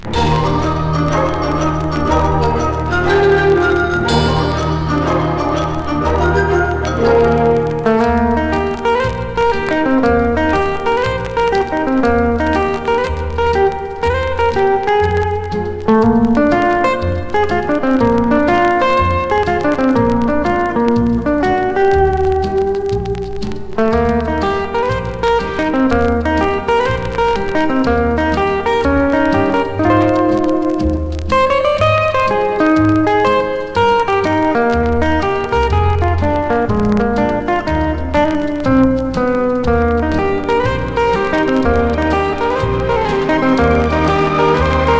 タイトル通りのハリウッド録音。
きめ細かく行き届いたギタープレイと、素晴らしいストリングスアレンジで非日常の夢見を与えてくれます。
Pop, Jazz, Country　USA　12inchレコード　33rpm　Stereo